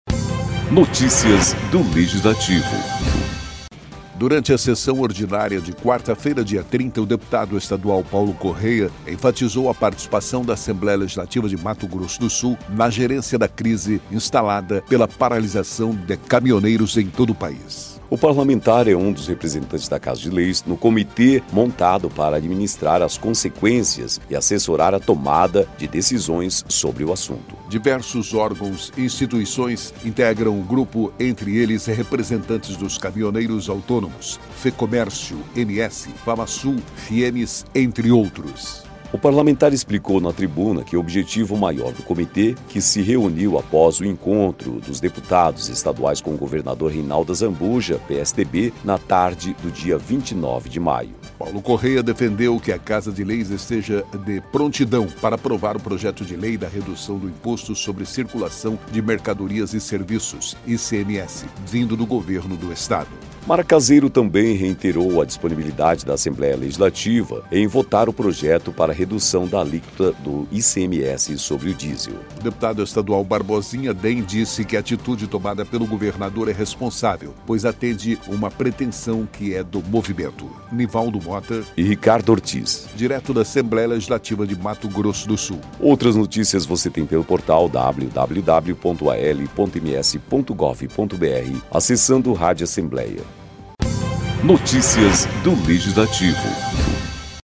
Durante a sessão ordinária desta quarta-feira (30), o deputado Paulo Corrêa (PSDB) enfatizou a participação da Assembleia Legislativa de Mato Grosso do Sul na gerência da crise instalada pela paralisação de caminhoneiros em todo o país.